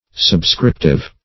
Search Result for " subscriptive" : The Collaborative International Dictionary of English v.0.48: Subscriptive \Sub*scrip"tive\, a. Of or pertaining to a subscription, or signature.